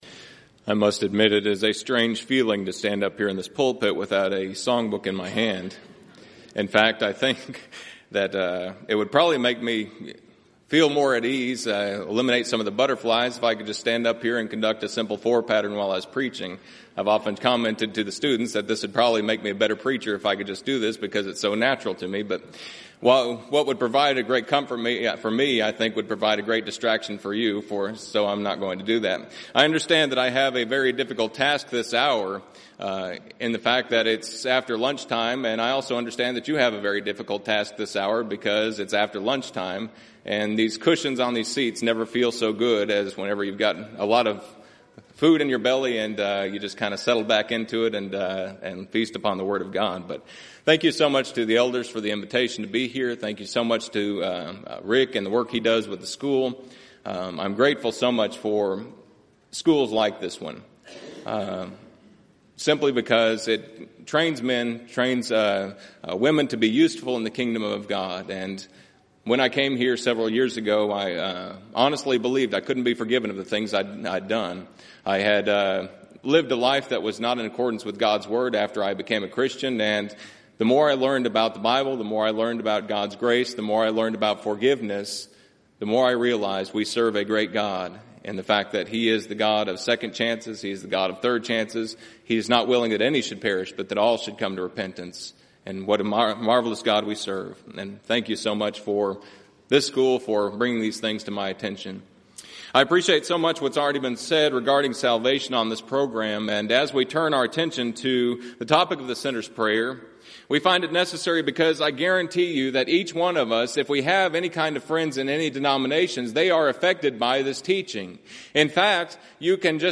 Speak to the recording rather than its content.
Event: 29th Annual Southwest Lectures